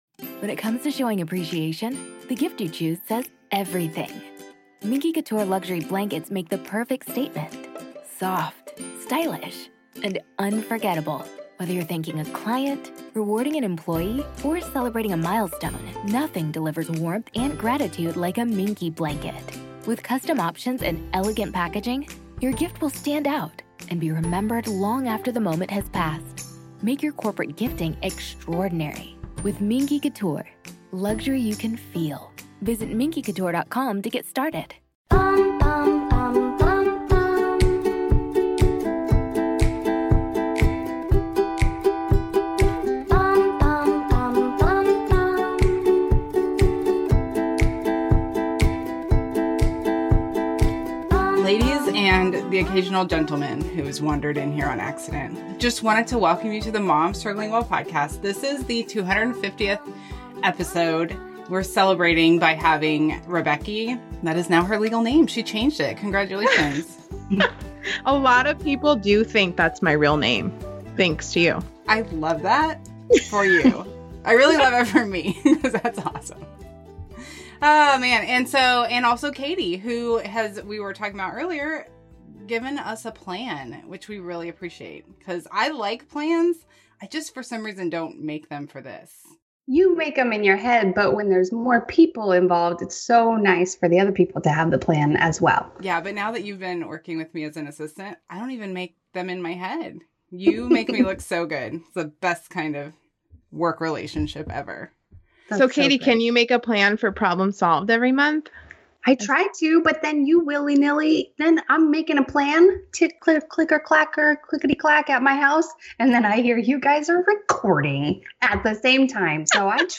We hope you guys get a chuckle out of this one because we laughed the entire time!